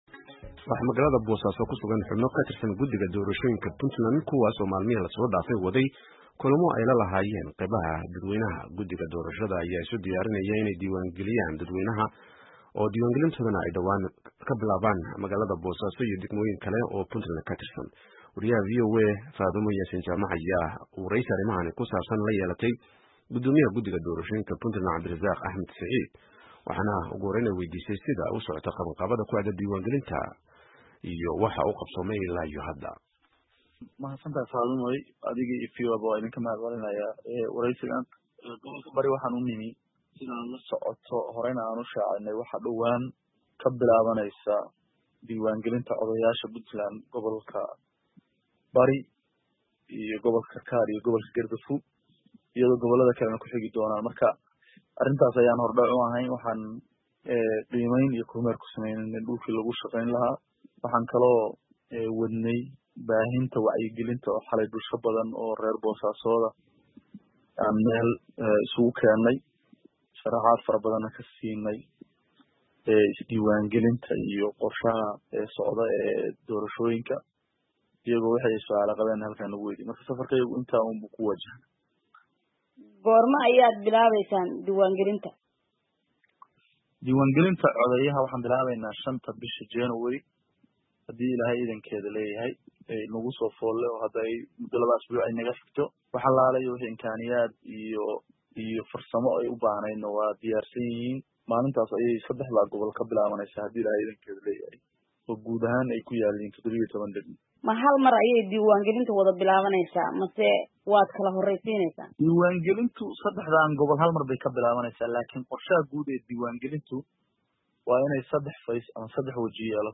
Wareysi: Guddoomiyaha Guddiga Doorashada Puntland